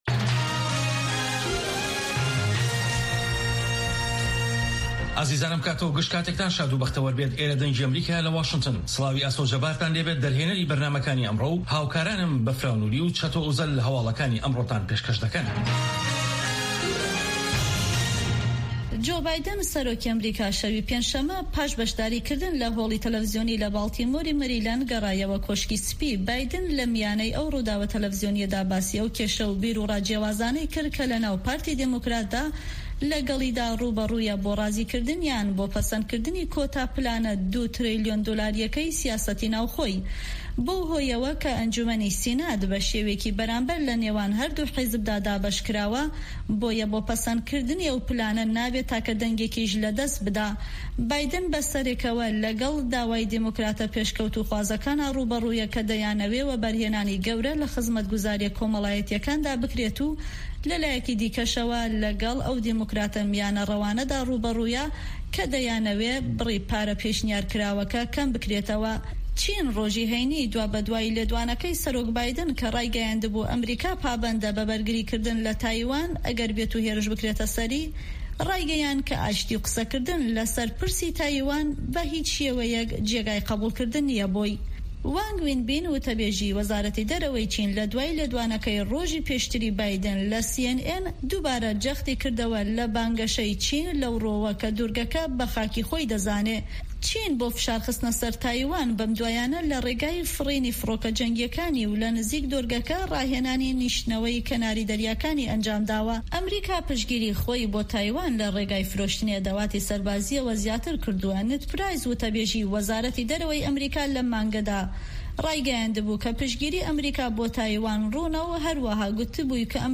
هه‌واڵه‌کان، ڕاپۆرت ، وتووێژ